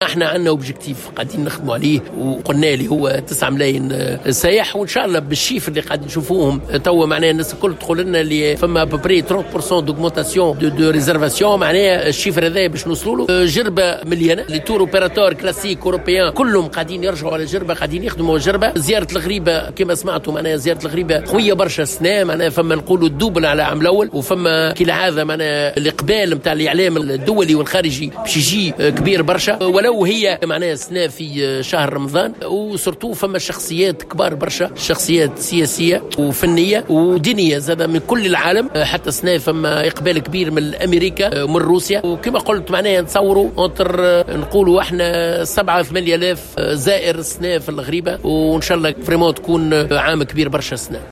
وأضاف في تصريح لمراسلة "الجوهرة أف أم" على هامش زيارة أداها اليوم إلى جزيرة جربة، أنه من المنتظر أيضا حضور شخصيات سياسية ودينية وفنية من عدة بلدان، خاصة من أمريكا وروسيا.